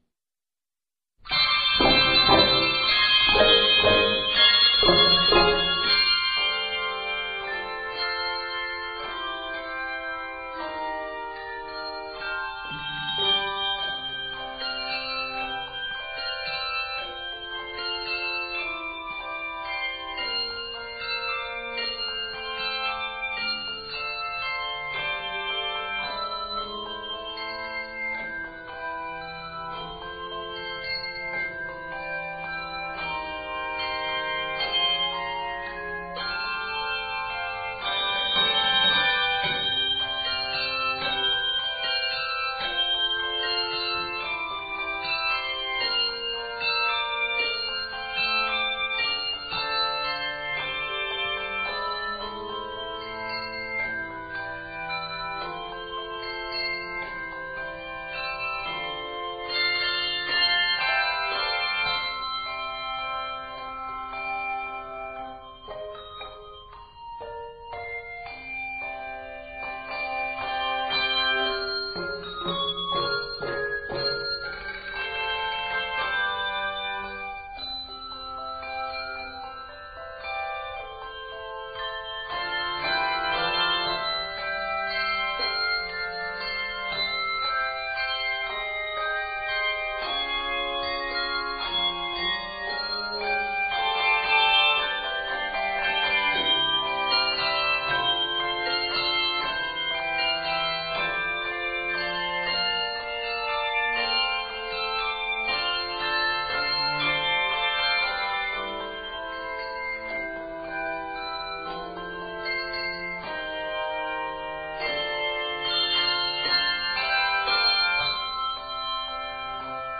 Review: Fast but rhythmically easy and melodic.